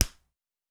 Ball Contact Skin.wav